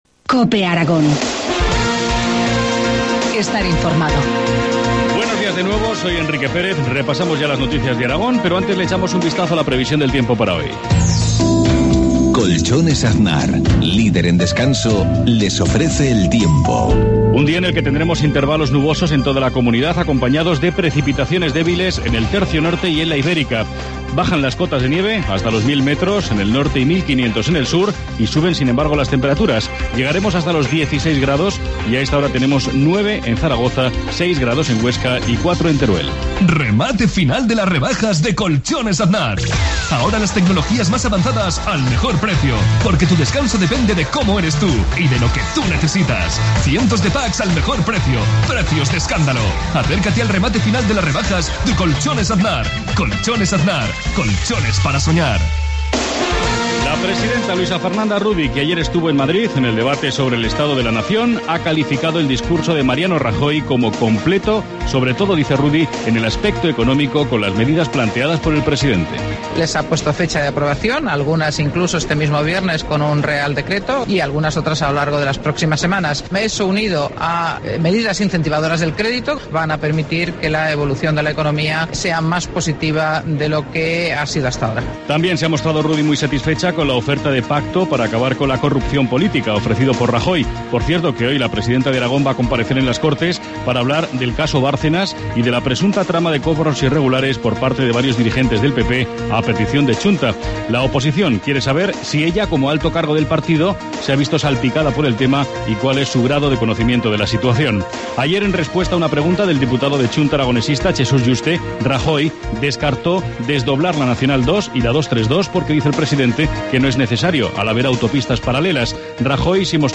Informativo matinal, jueves 21 de febrero, 7.53 horas